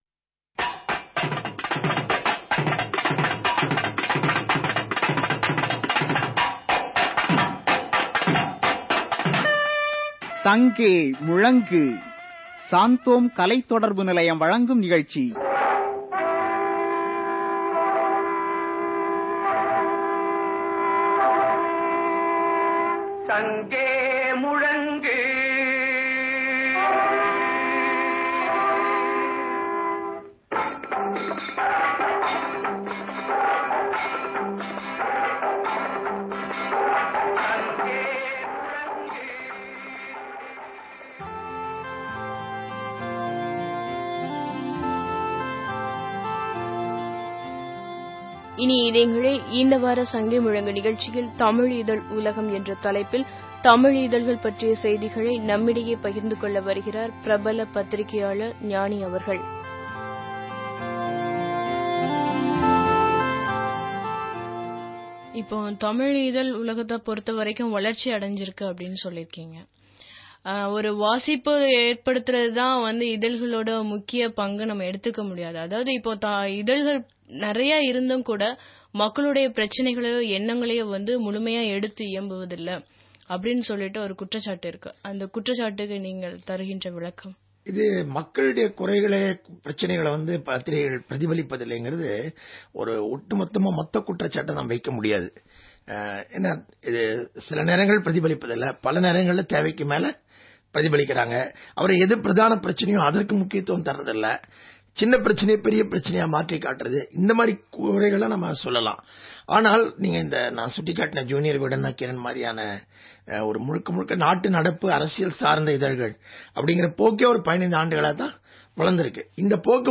Directory Listing of mp3files/Tamil/People's Voice (SANGEA MUZHNGU)/Gnani Interview/ (Tamil Archive)